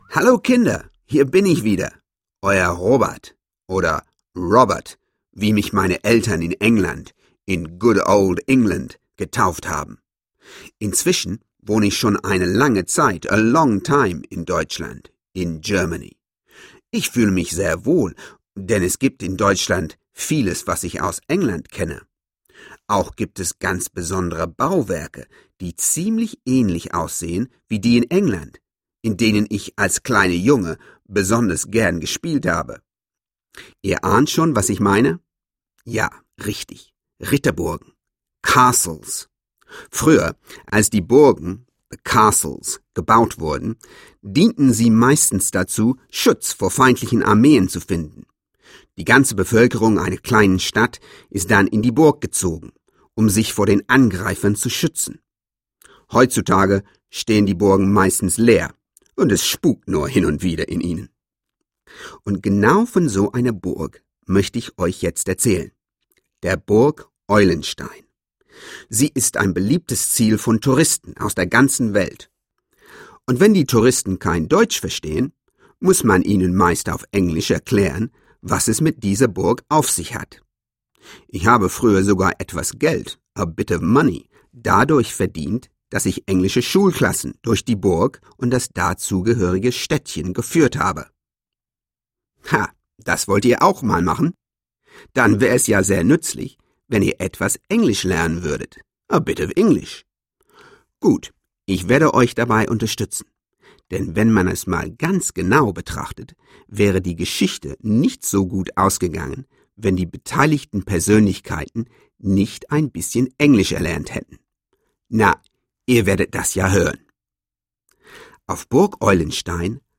Sprach-Hörspiel für Kinder nach Motiven des gleichnamigen Kinderbuchs von Otfried Preussler (1 CD)
Dazu jede Menge Lieder zum Mitsingen!